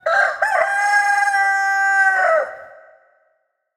Ringetone Krage
Kategori Dyr
krage.mp3